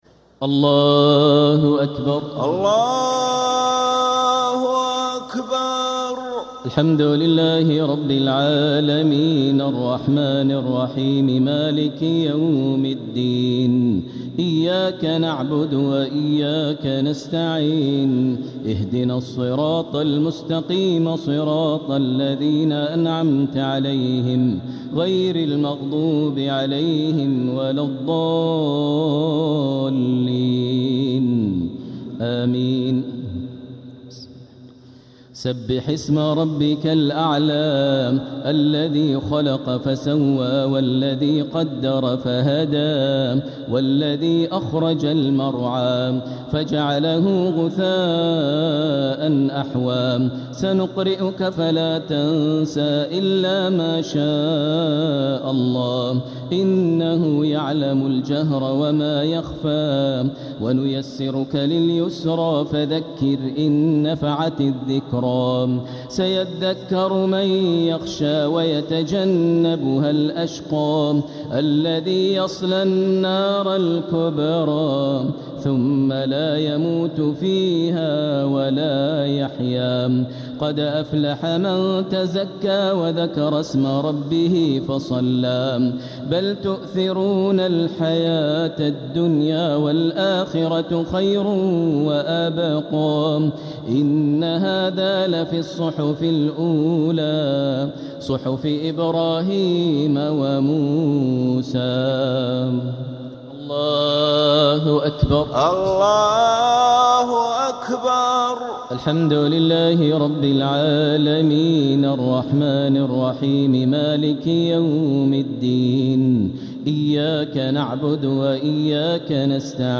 صلاة الشفع و الوتر ليلة 22 رمضان 1447هـ | Witr 22nd night Ramadan 1447H > تراويح الحرم المكي عام 1447 🕋 > التراويح - تلاوات الحرمين